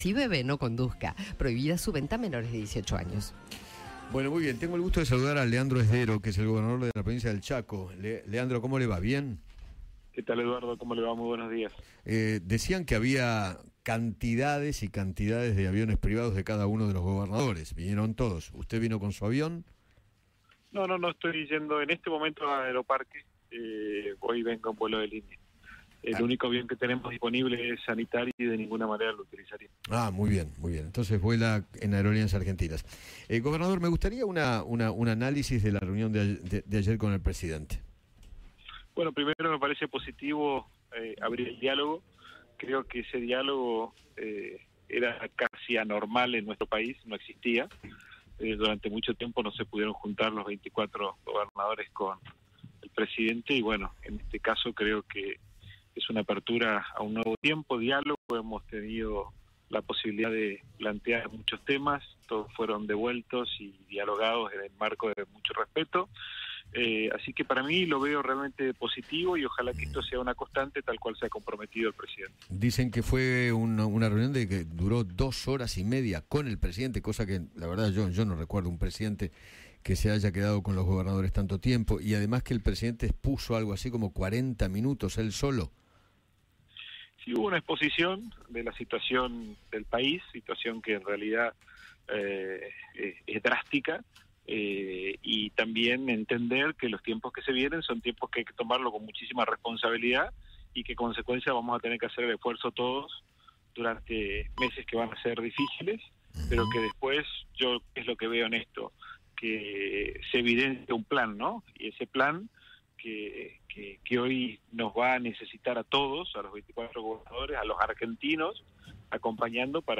Leandro Zdero, gobernador de Chaco, conversó con Eduardo Feinmann sobre la reunión que mantuvieron los mandatarios provinciales con el presidente.